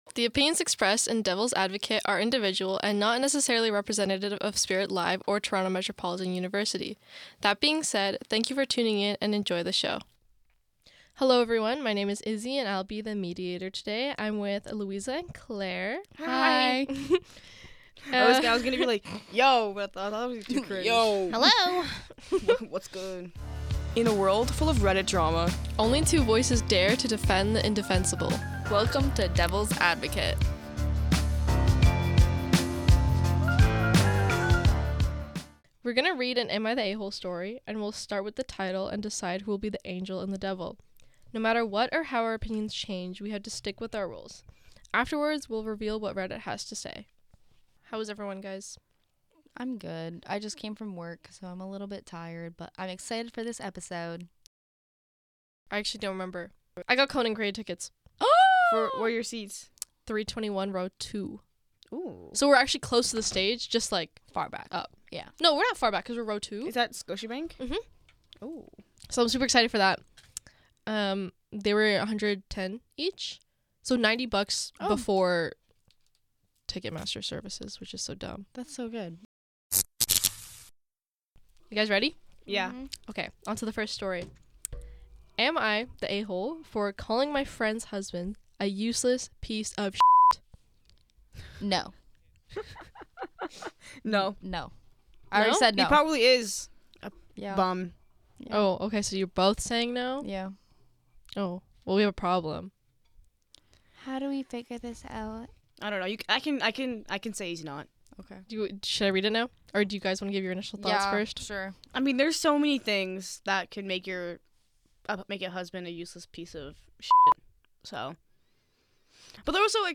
Each week three hosts will debate and discuss a Reddit post from the thread “Am I an A-hole?”. These debates will be between two hosts, one against and one for, while the third host reads, moderates and acts as a commentator of the discussion.